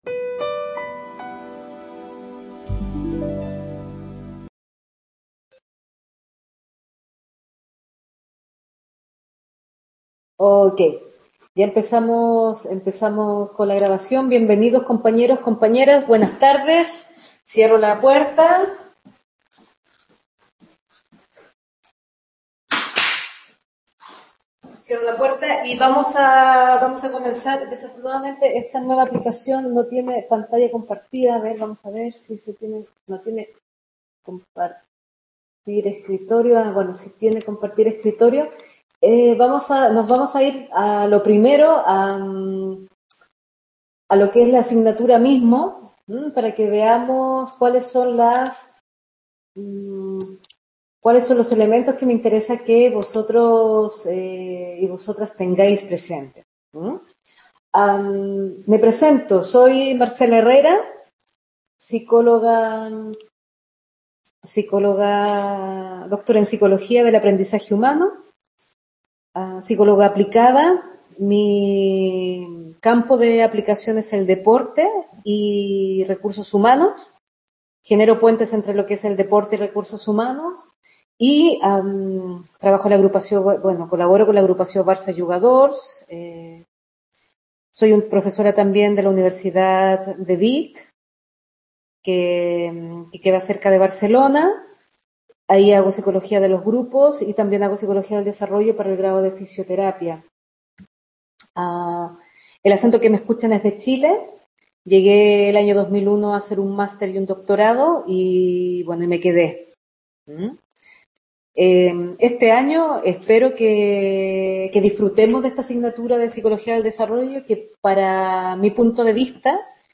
Clase introductoria de la asignatura